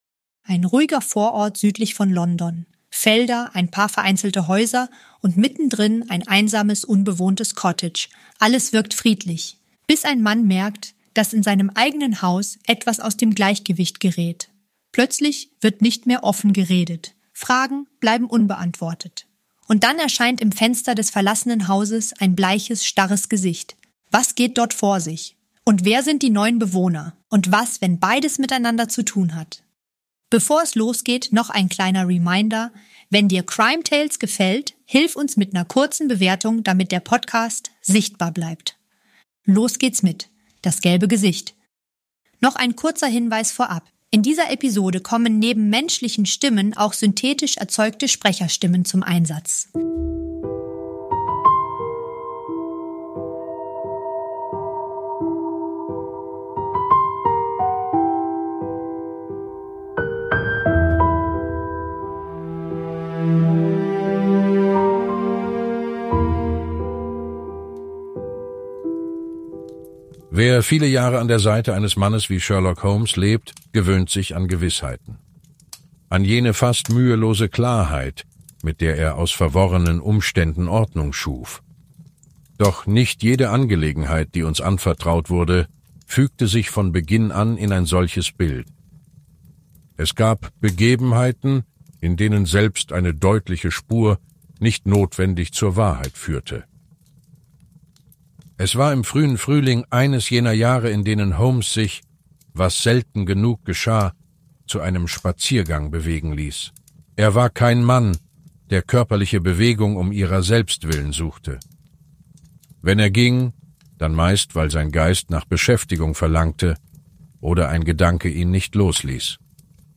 - In dieser Produktion kommen neben unseren eigenen Stimmen auch synthetische Sprecherstimmen zum Einsatz.